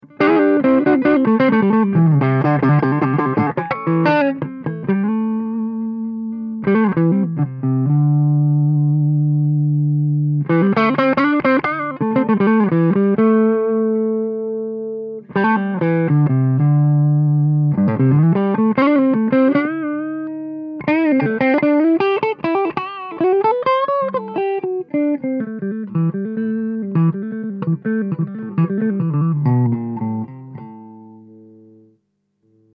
- Copie du son du canal "Blue" du Bogner XTC.